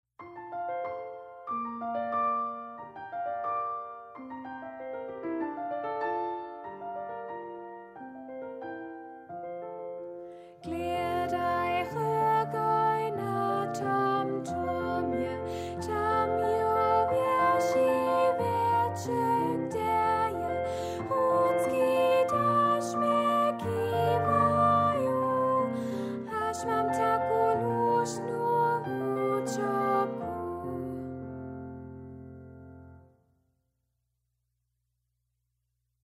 spiwy za pógibowanje a rejowanje
melodija: z ludu